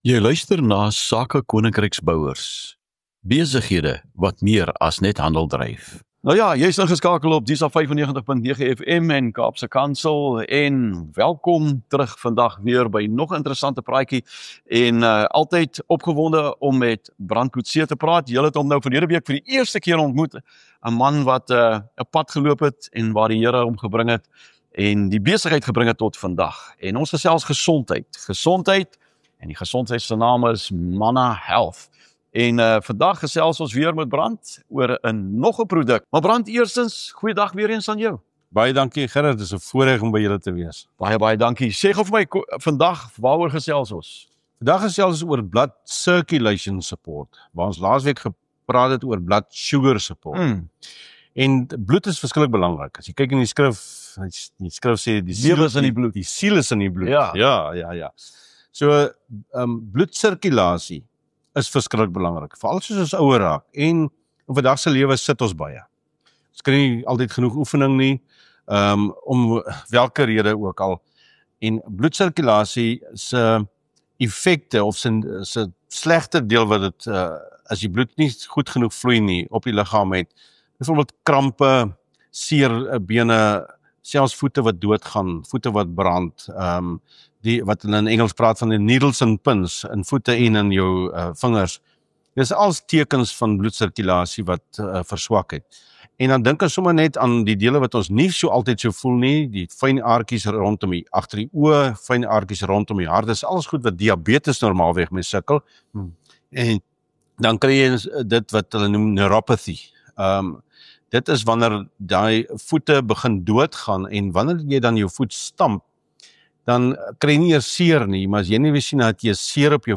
’n Praktiese en insiggewende gesprek oor hoe om gesondheid en welstand te ondersteun deur wetenskaplik-gebaseerde natuurlike oplossings.